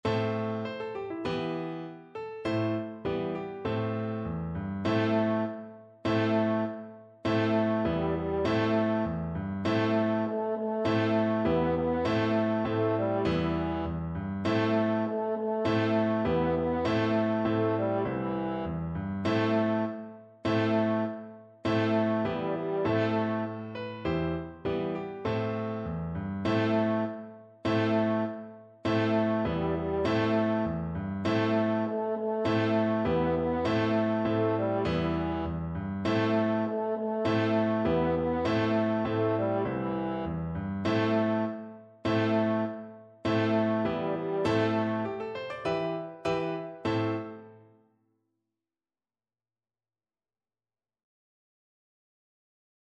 French Horn
2/4 (View more 2/4 Music)
Firmly =c.100
Traditional (View more Traditional French Horn Music)